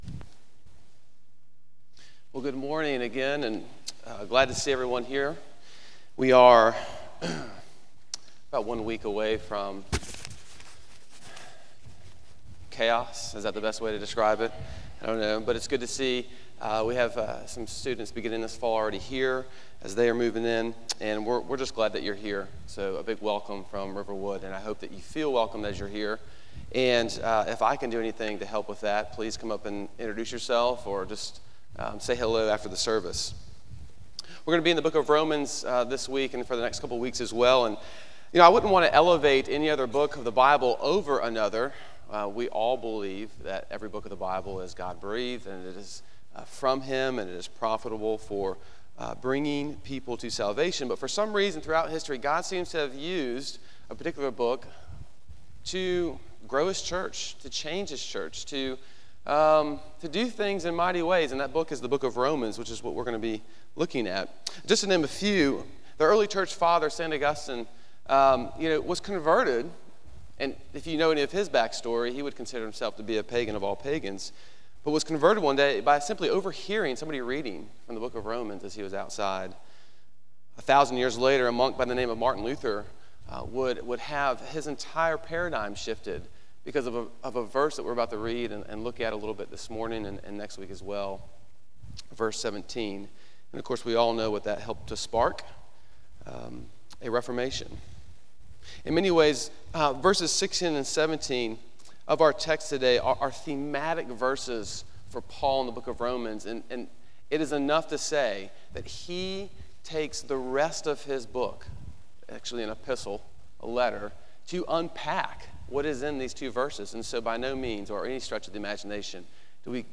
Sermon on Romans 1:1-4